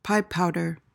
PRONUNCIATION:
(PY-pow-duhr)